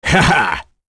Phillop-Vox-Laugh1.wav